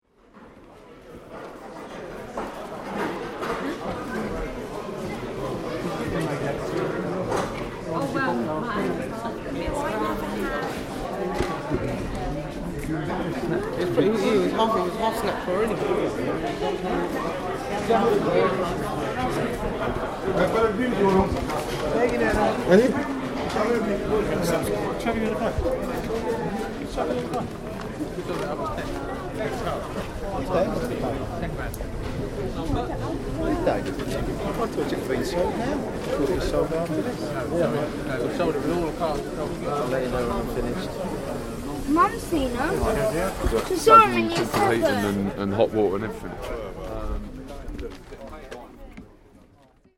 Chatter cross section of auction